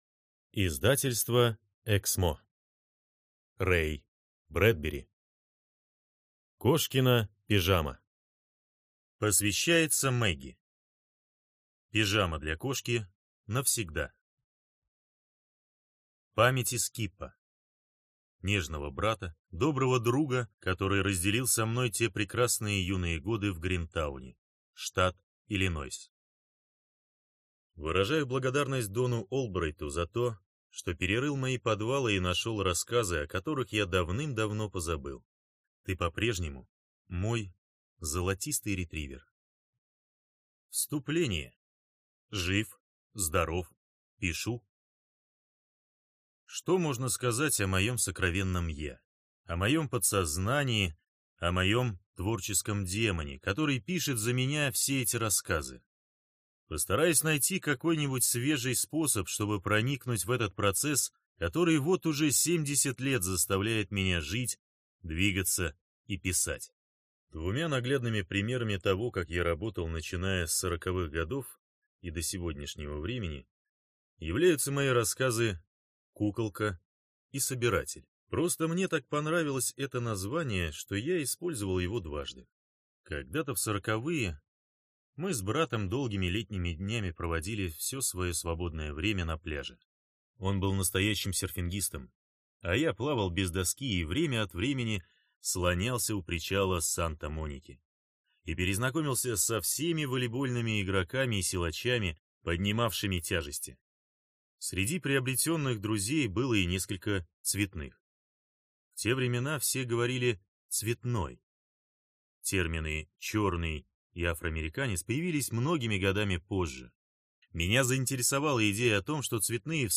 Аудиокнига Кошкина пижама (сборник) - купить, скачать и слушать онлайн | КнигоПоиск